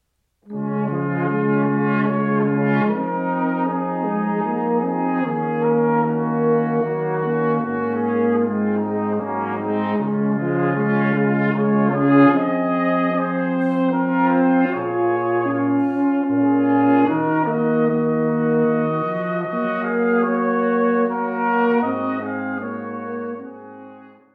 Choräle & Balladen von klassisch bis modern